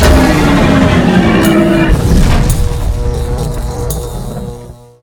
combat / enemy / droid / bigdie3.ogg
bigdie3.ogg